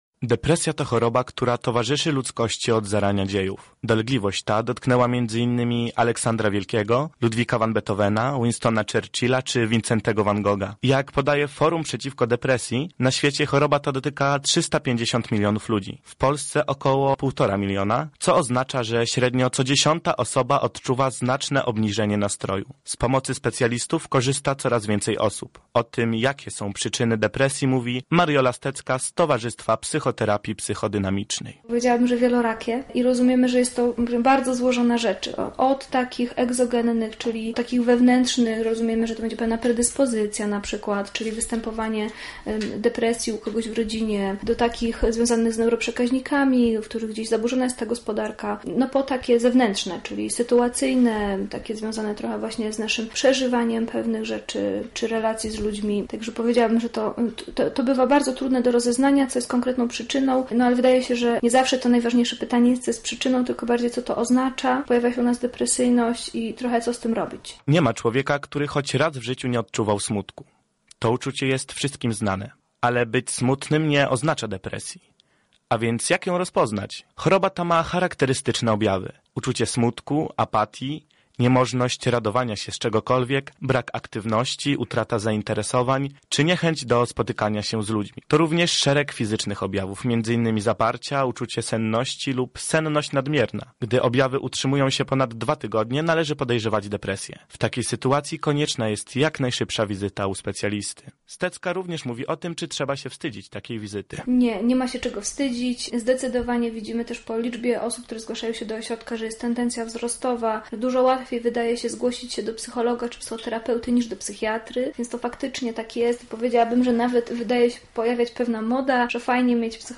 Więcej na ten temat usłyszycie w materiale naszego reportera: